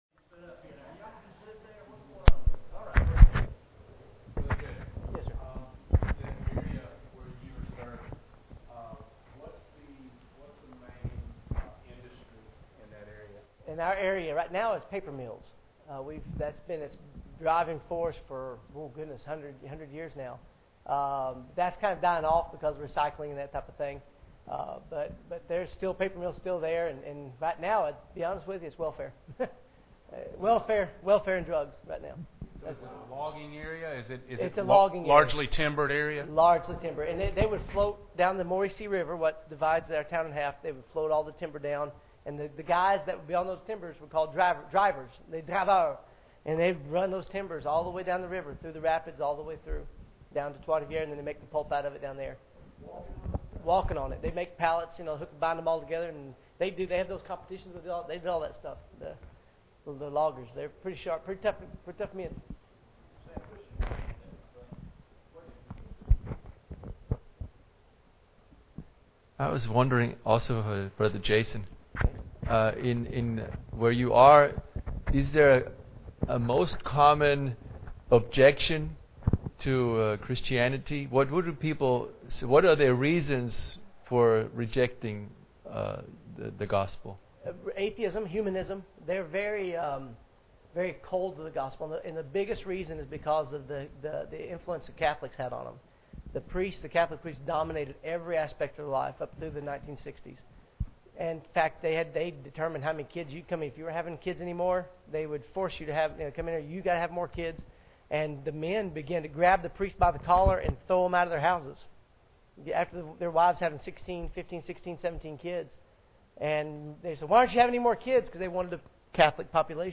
Preacher: 2010 Missionary Panel | Series: 2015 Missions Conference
Note: Due to sharing and moving microphones there are some sound artifacts present in the recording.
Service Type: Sunday Evening